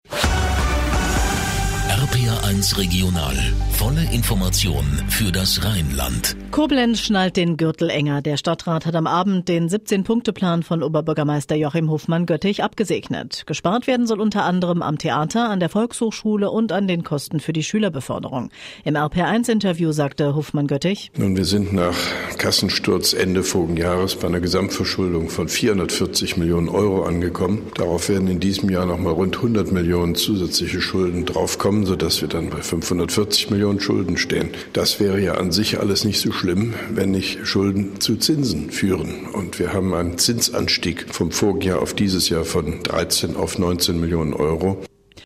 Ausschnitt: RPR1 Regional, Informationen für das Rheinland, Studio Koblenz, 02.02.2012
Mit einem Kurzinterview von OB Hofmann-Göttig